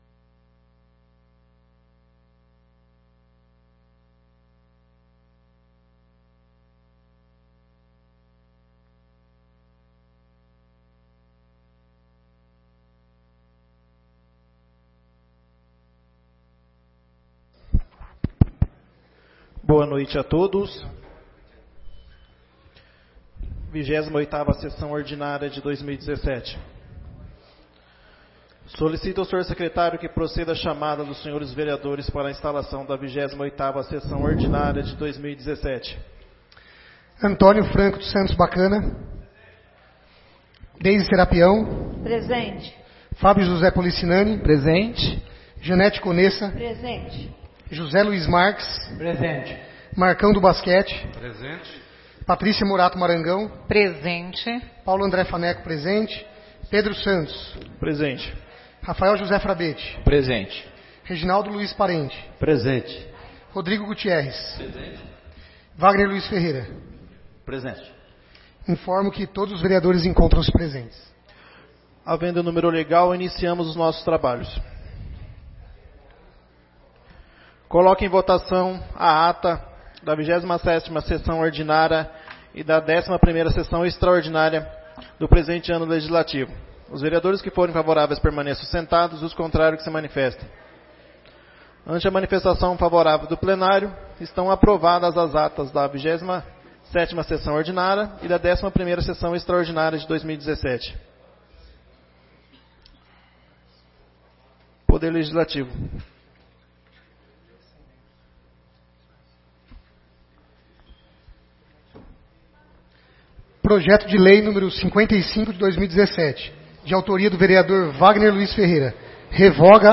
28ª Sessão Ordinária de 2017